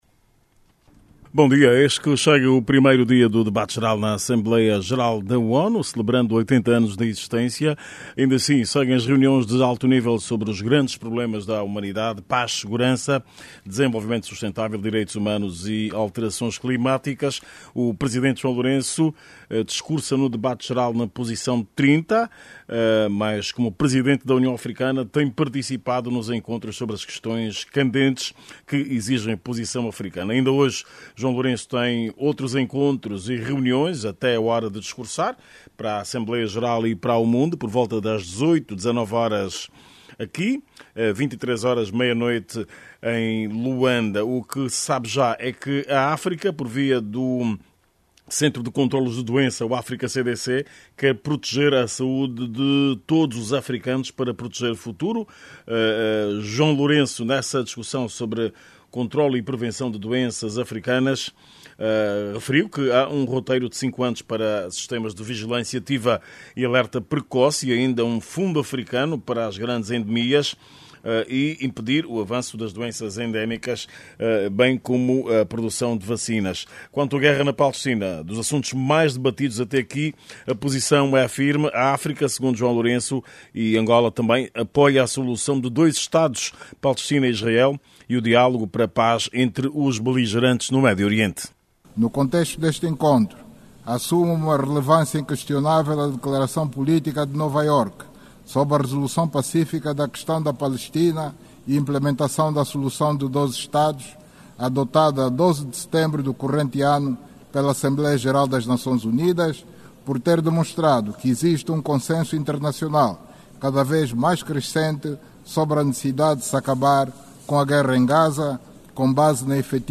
a partir de Nova Yorque.